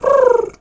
pigeon_2_call_calm_03.wav